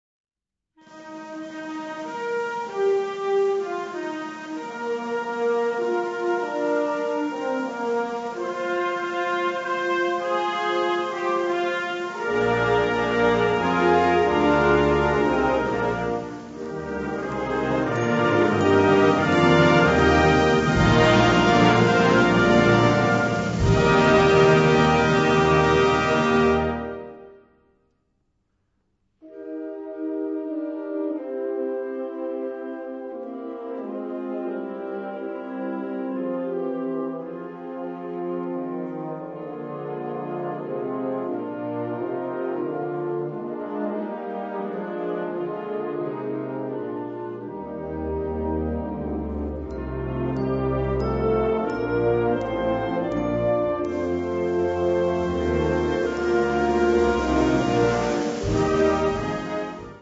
Categorie Harmonie/Fanfare/Brass-orkest
Subcategorie Feestelijke muziek, fanfare, hymne